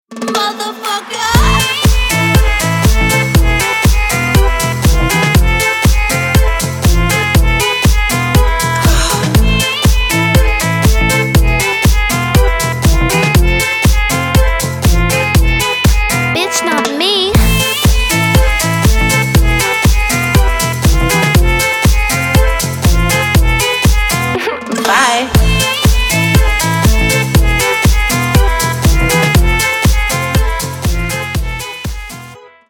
Поп Музыка
без слов # восточные